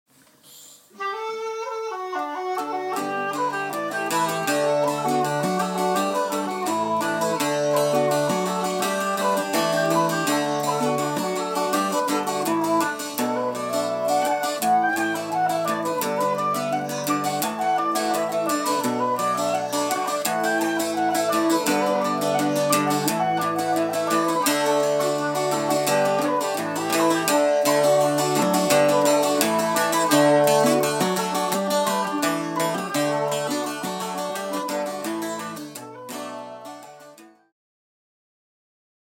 Bouzouki Accompaniment to Irish Music - Master Basic Skills for Any Tune
Bouzouki-Basics-2.mp3